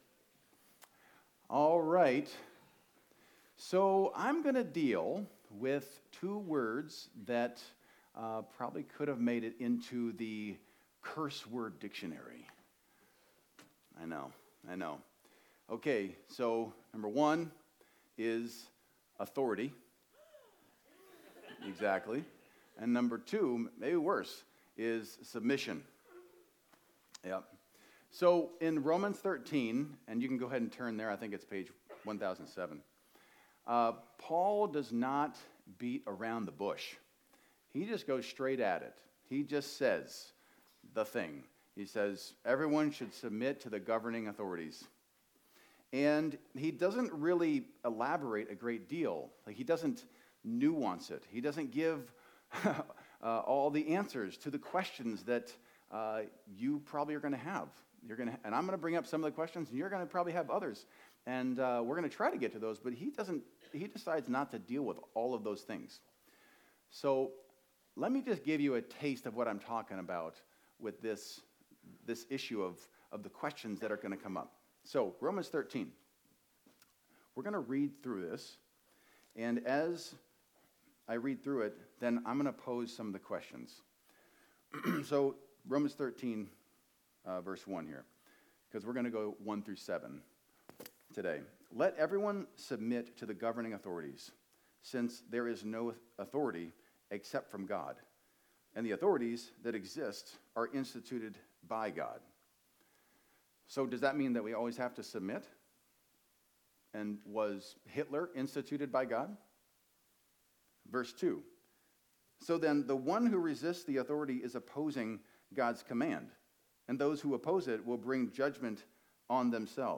Video Audio Download Audio Home Resources Sermons Do we always have to submit to authority?